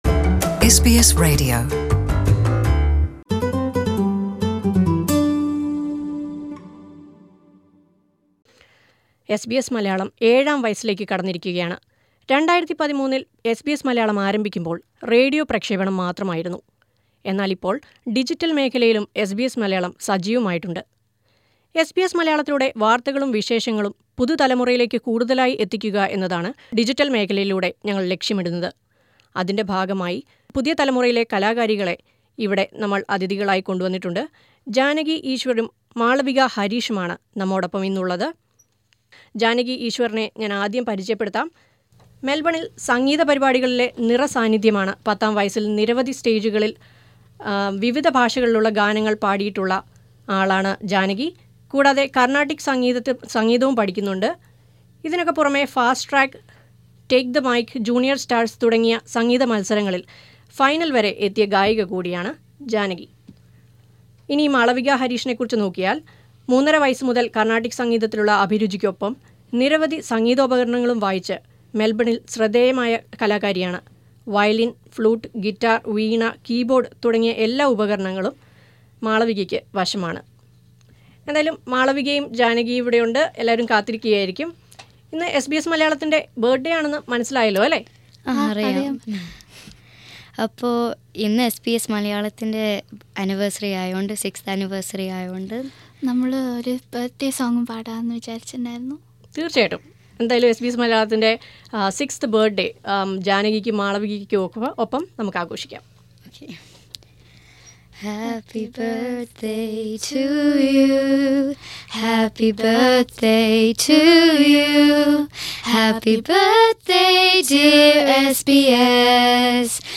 SBS Malayalam turned 7years on May 16. As part of extending our reach to the new generation two little musicians were brought to the studio as guests.